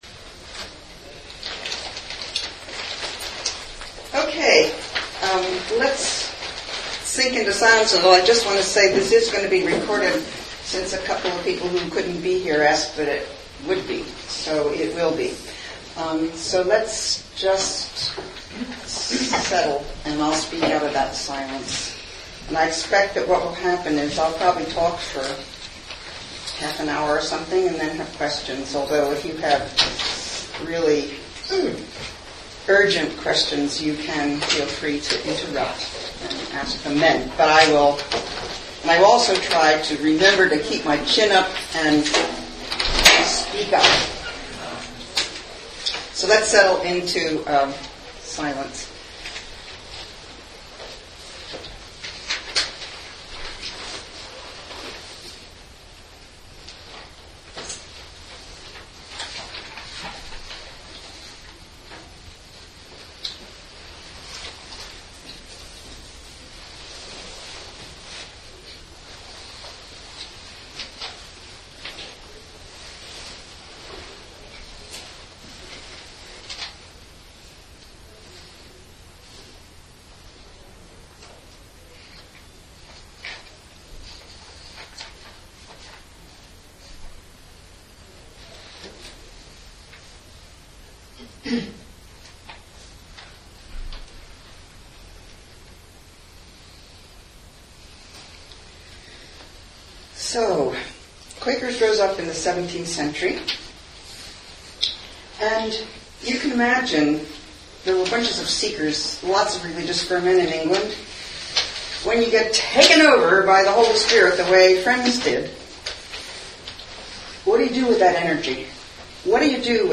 This is a one-hour MP3 audio file with a talk followed by questions and discussion. From Rules of Discipline to books of Faith and Practice : continuing revelation in New England Yearly Meeting from 1809 onward :